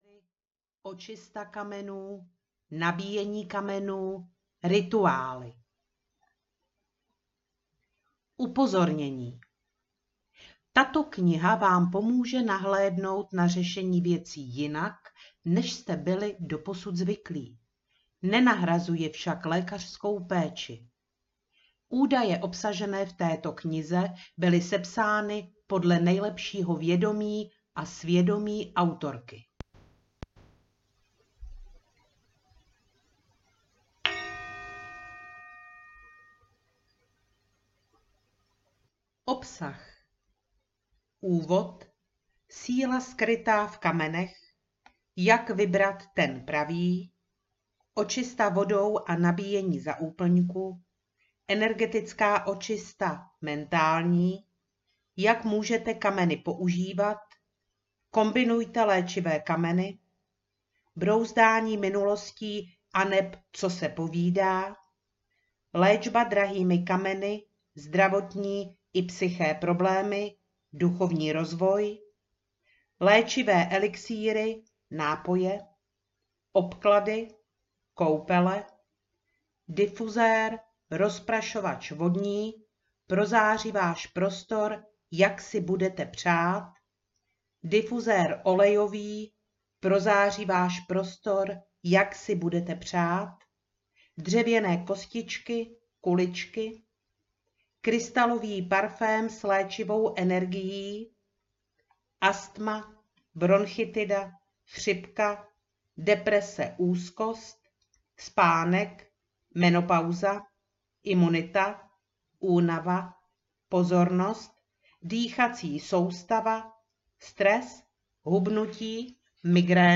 Mocná síla kamenů audiokniha
Ukázka z knihy